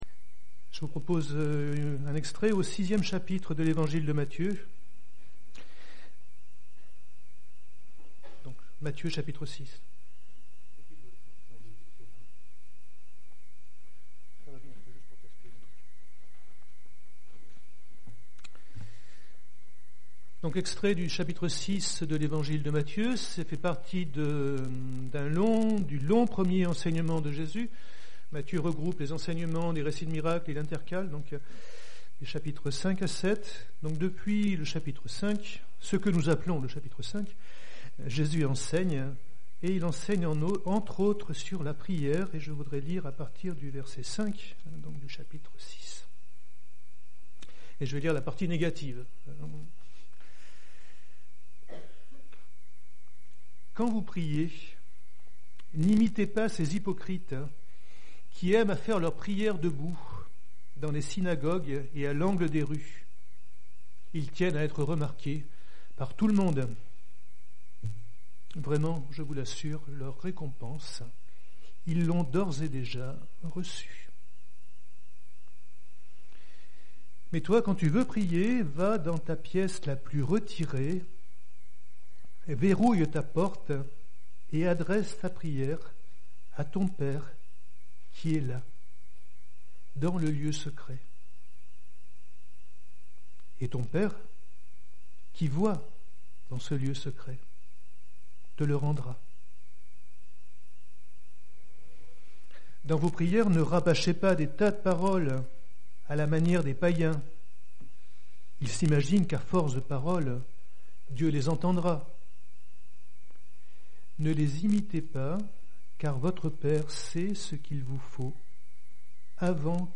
Quelques brèves dissonances au milieu du message